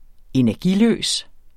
Udtale [ -ˌløˀs ]